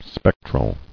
[spec·tral]